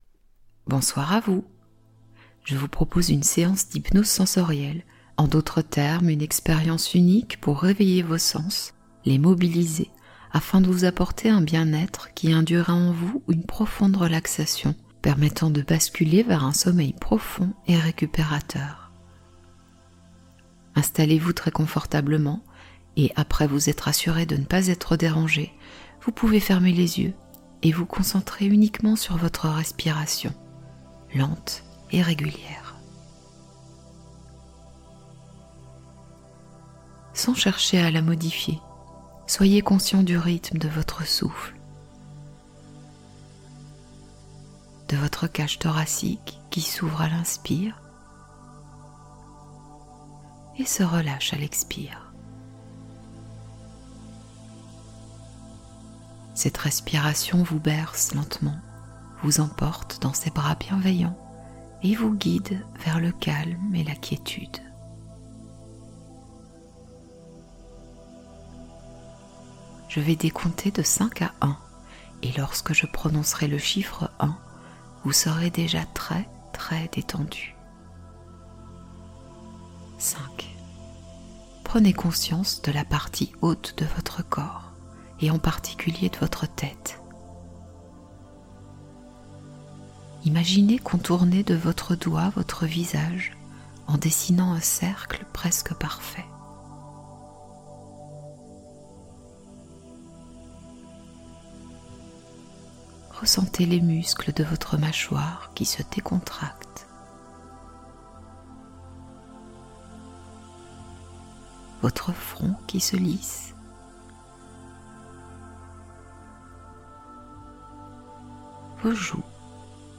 INÉDIT : Dormez bercé par la nature | Hypnose sons eau, oiseaux, pluie... pour sommeil magique ⭐